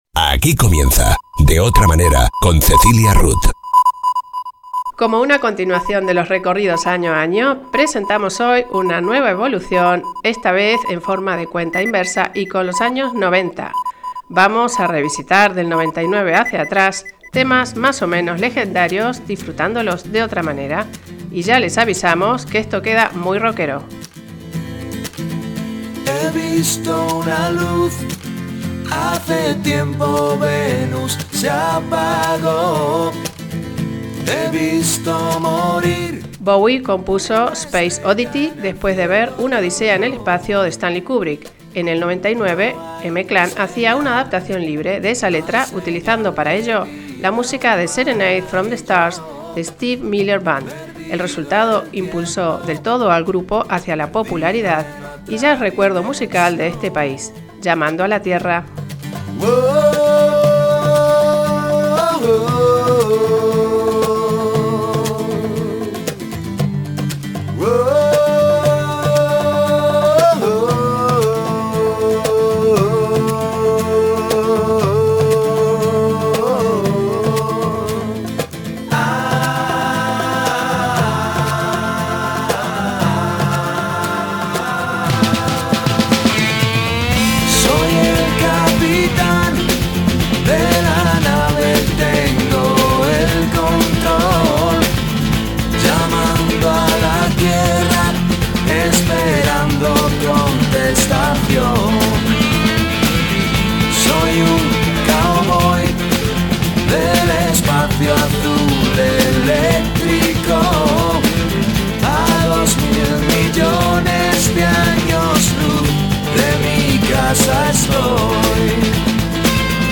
Vamos a revisitar, del 99 hacia atrás, temas más o menos legendarios disfrutándolos… de otra manera. Y ya les avisamos que esto queda muy rockero.